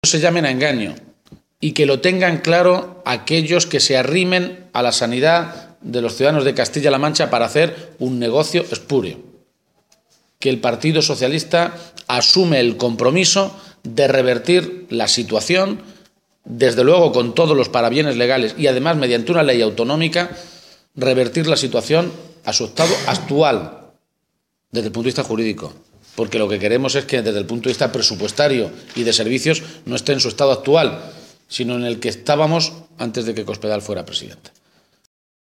Emiliano García-Page ha realizado estas declaraciones antes de reunirse con la Coordinadora en Defensa del Hospital Público de Almansa, a quienes ha transmitido dos compromisos: “que en el primer año de legislatura de reconstrucción de Castilla-La Mancha vamos a revertir el proceso de negocio que quieren hacer algunos con la sanidad. También pelearemos en vía judicial para que no se produzca el uso abusivo del negocio con la sanidad que quiere hacer Cospedal”.